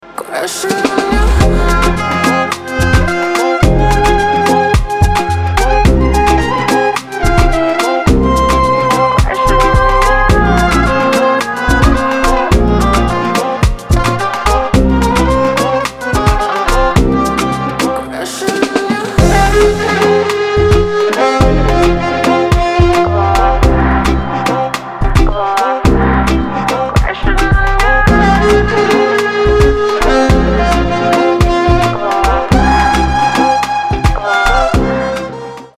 • Качество: 320, Stereo
чувственные
восточные
красивый женский голос
ремиксы
Чувственный восточный рингтон